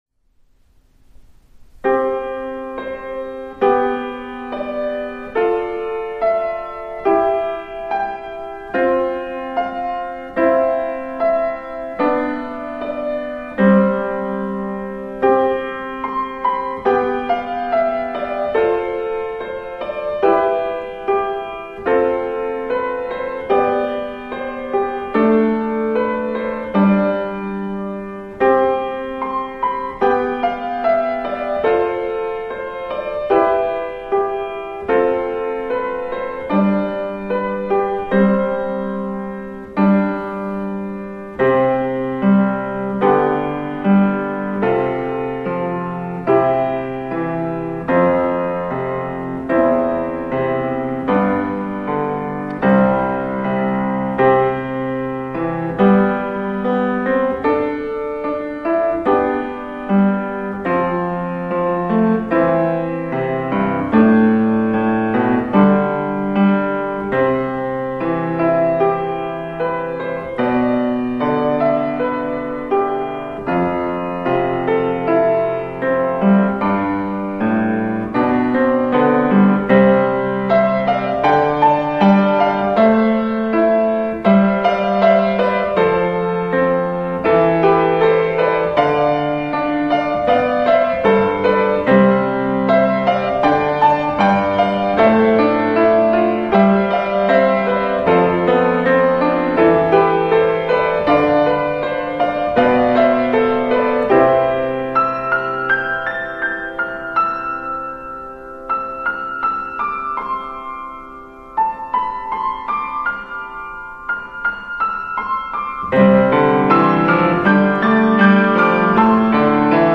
スタインウェイD274でオリジナル曲を2曲ピアノ演奏
曲調としては静かでスローテンポな曲で、終盤にセンチメンタル？に盛り上がっていくような感じのピアノ曲です。
(どちらかというと失敗をしないように慎重に弾いていたので、ダイナミックさに欠けるところがありますが、、)
ピアノ曲夜は朝を迎えるを再生して聴いていると、1分19秒あたりからクライマックスに突入、やることが多いい、、